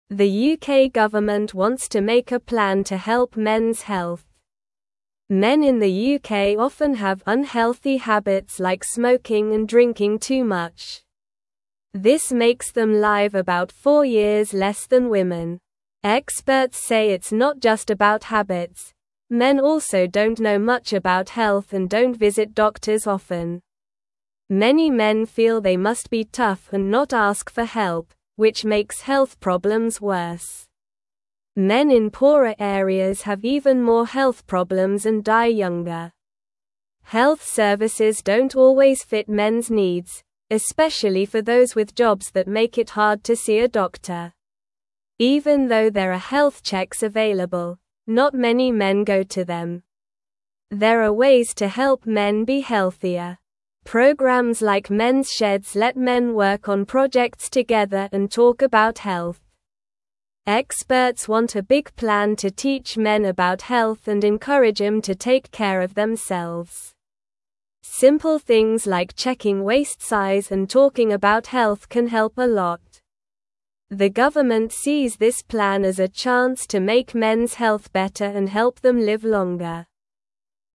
English-Newsroom-Lower-Intermediate-SLOW-Reading-Helping-Men-Be-Healthier-in-the-UK.mp3